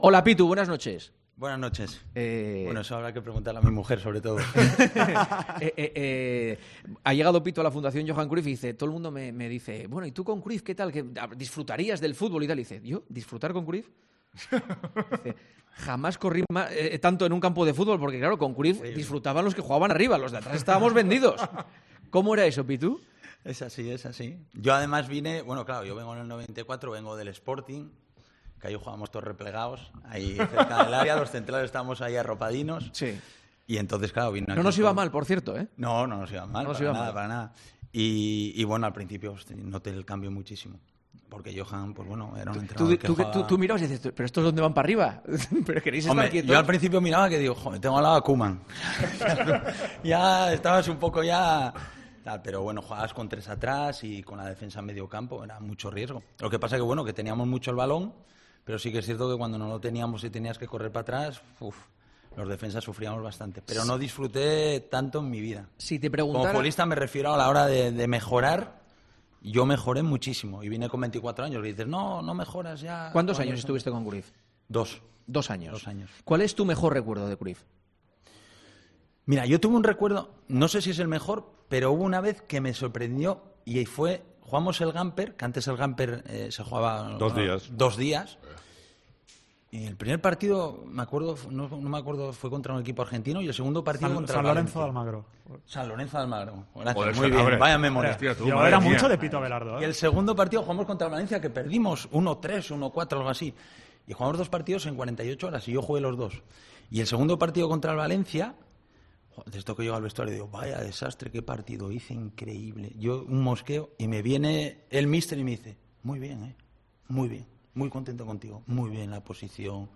ESCUCHA LA ENTREVISTA COMPLETA EN 'EL PARTIDAZO DE COPE' Pese a su dureza, " no solía meter broncas.